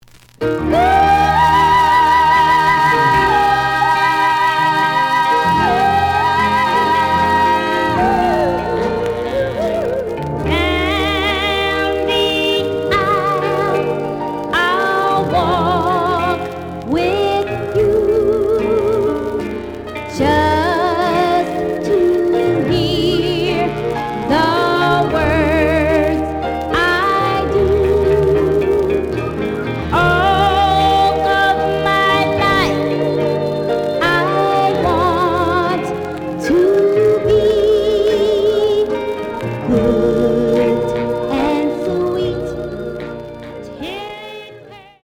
The audio sample is recorded from the actual item.
●Genre: Soul, 60's Soul
Slight noise on A side.)